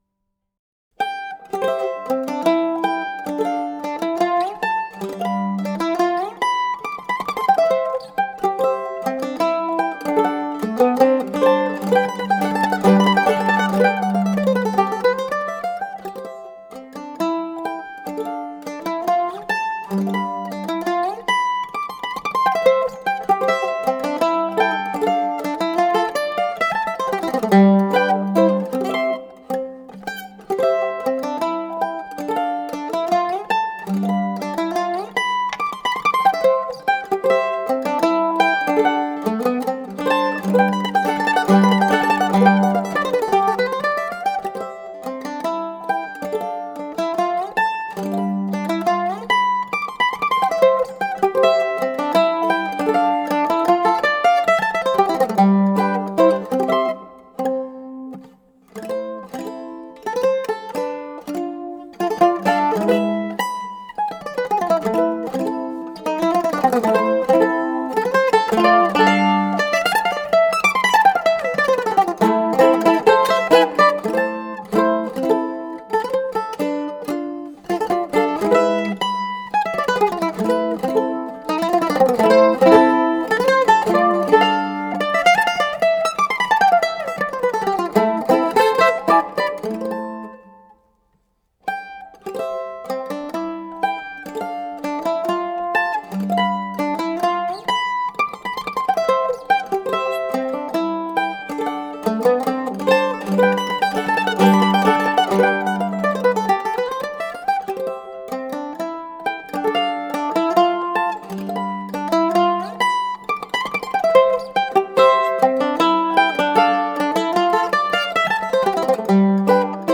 solo mandolin